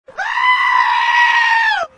Goat 2 Sound Effect Free Download
Goat 2